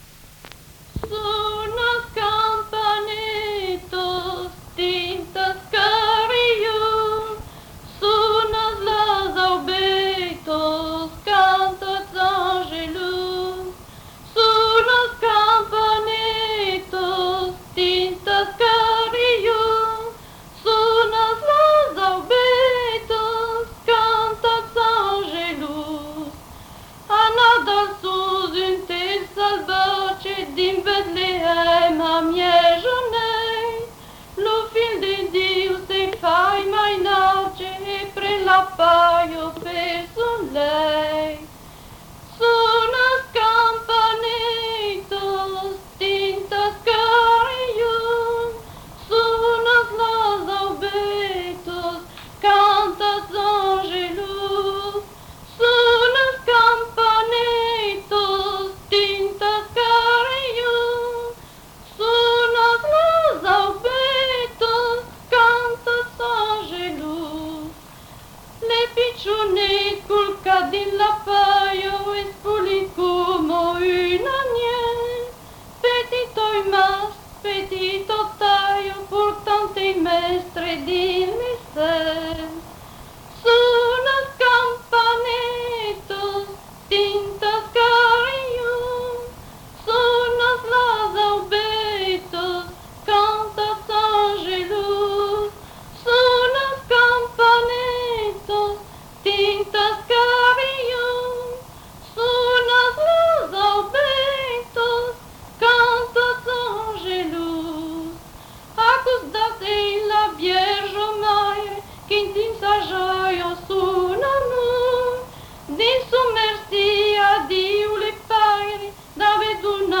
Aire culturelle : Cabardès
Lieu : Mas-Cabardès
Genre : chant
Effectif : 1
Type de voix : voix de femme
Production du son : chanté
Classification : noël